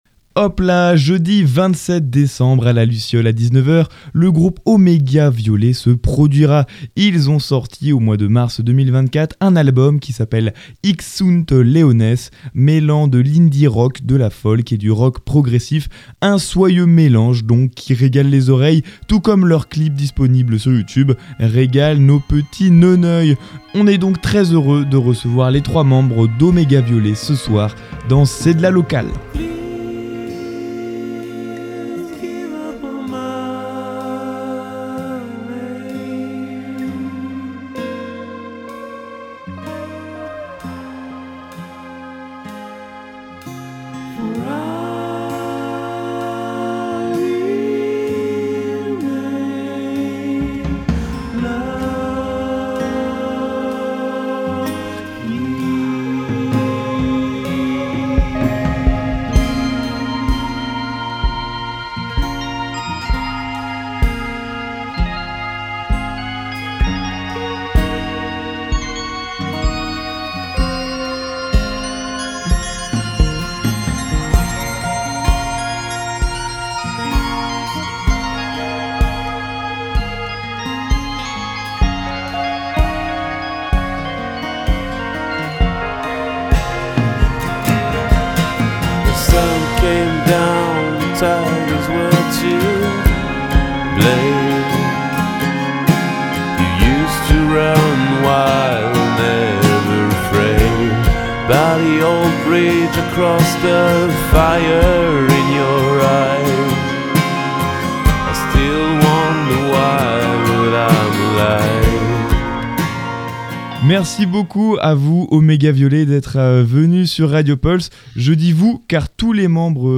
de l'indie rock, de la folk et du rock progressif